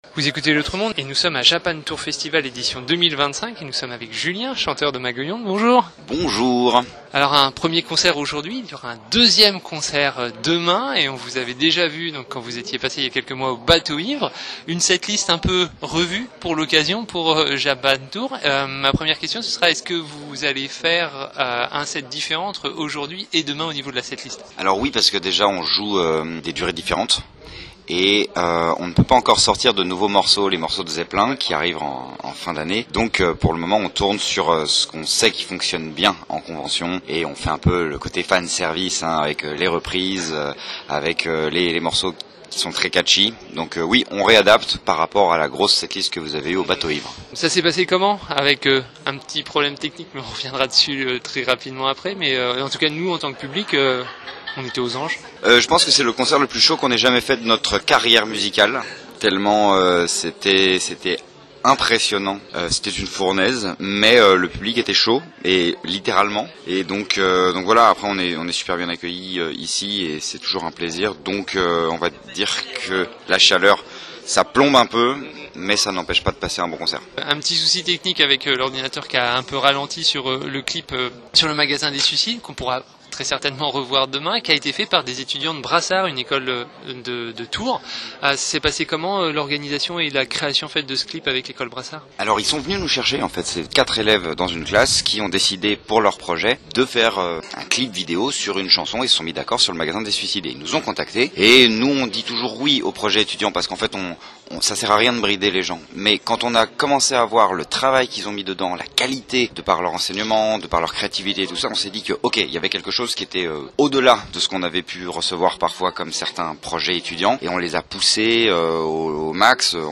Interview
enregistrée lors de leurs concerts à Japan Tours Festival